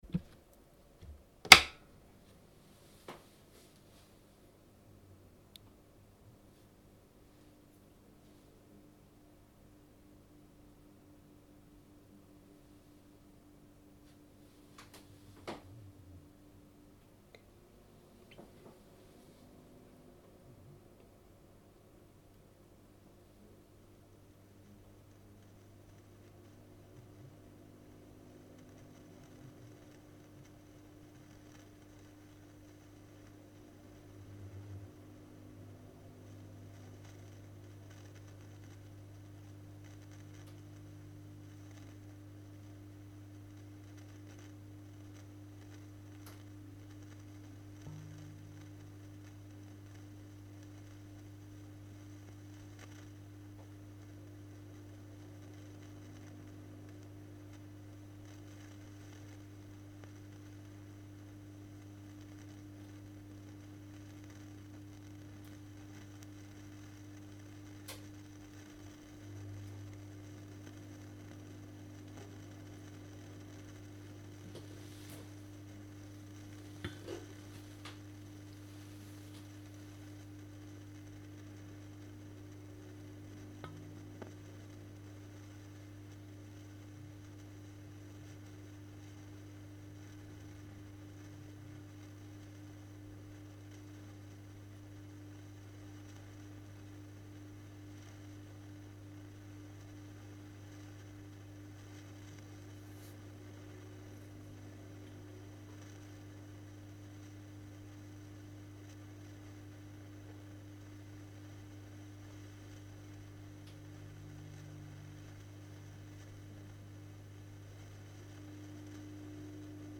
Bref, comme vous pourrez l'entendre, c'est bien plus faible qu'avant. Ca apparaît vers les 35 secondes, puis après quelques minutes, tout se stabilise et on entend qu'un souffle régulier.
Crachottement faible.mp3